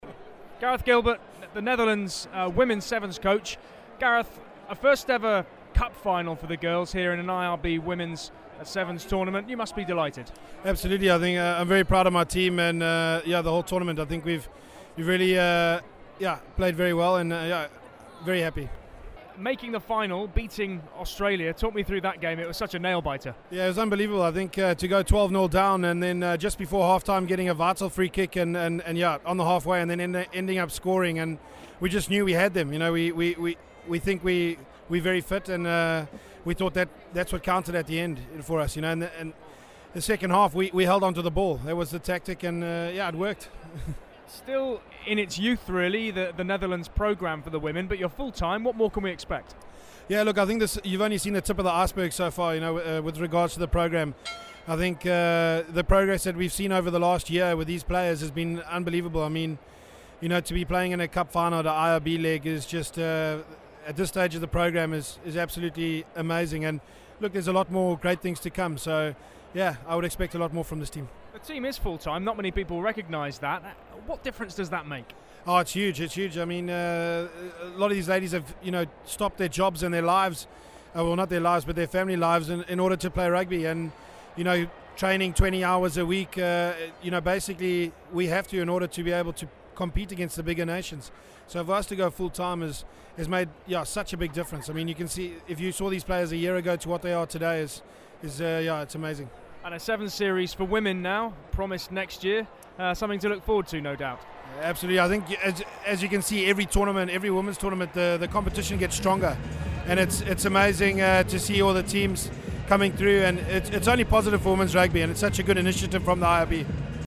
Interview
na Twickenham Finale